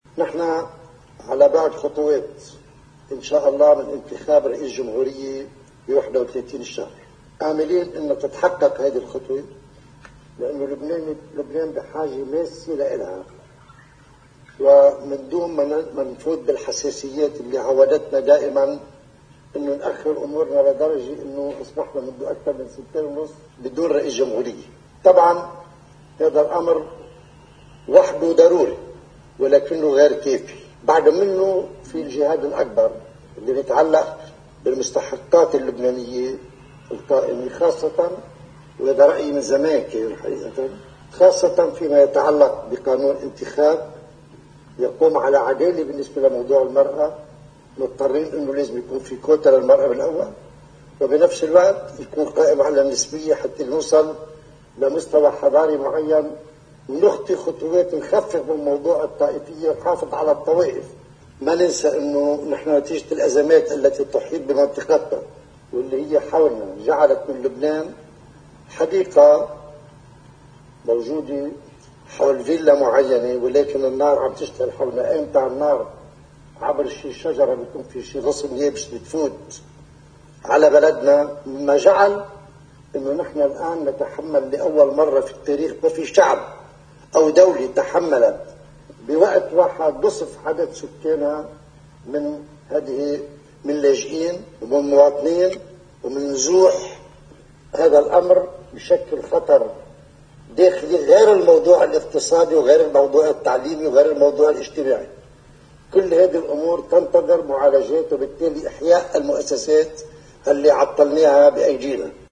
الرئيس بري خلال مأدبة عشاء اقامتها سفيرة لبنان لدى الأمم المتحدة في جنيف: